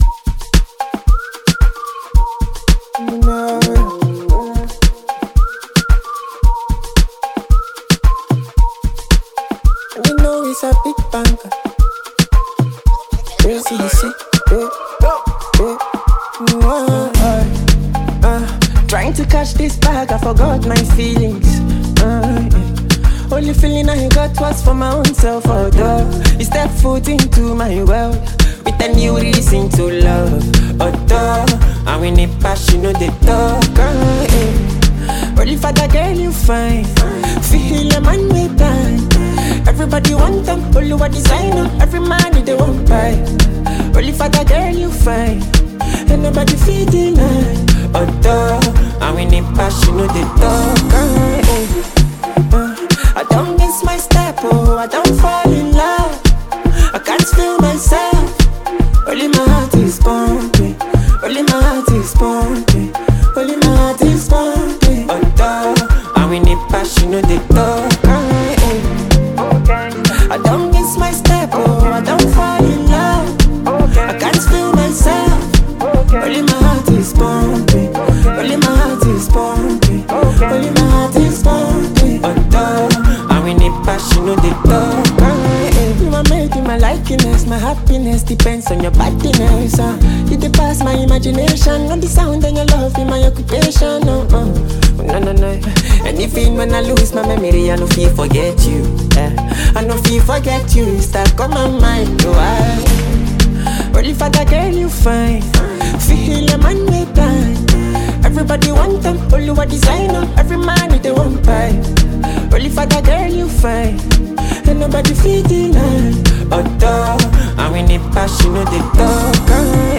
a Ghanaian angelic singer
is a dope Afrobeat tune from his camp